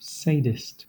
Ääntäminen
IPA : /ˈseɪdɪst/